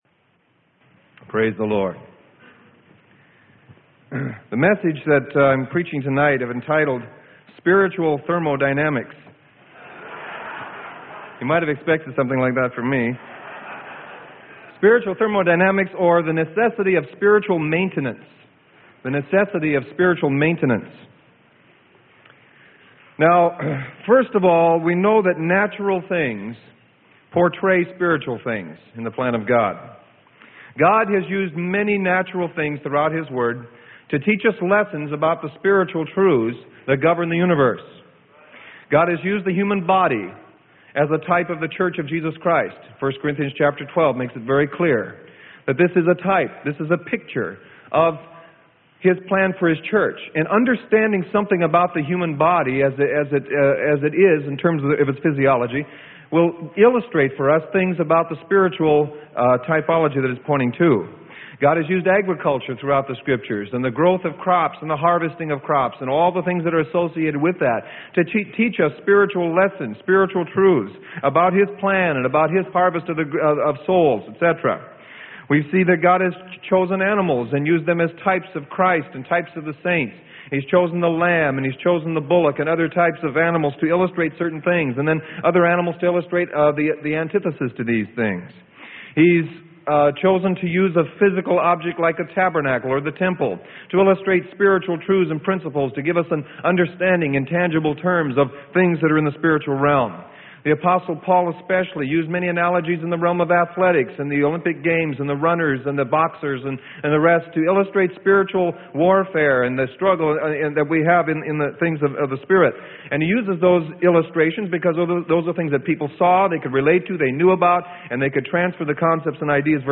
Sermon: Spiritual Thermodynamics - Freely Given Online Library